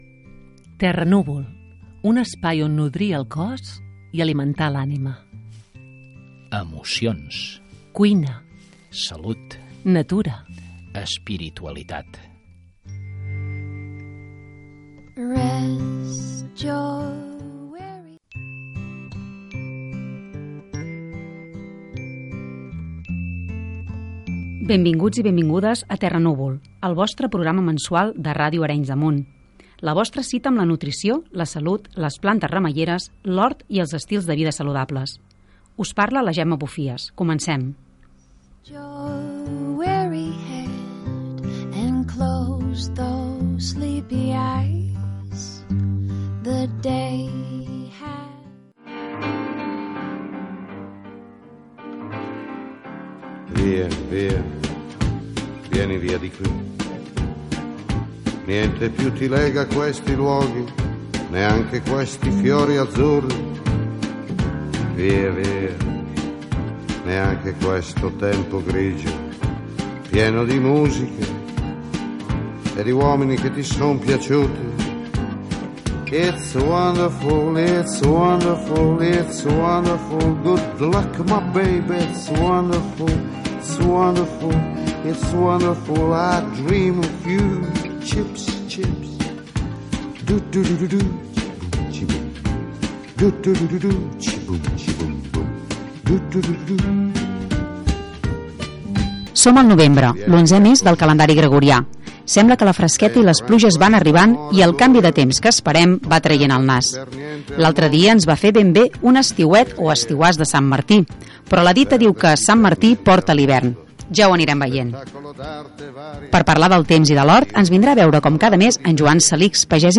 Careta del programam presentació, tema musical, sumari de continguts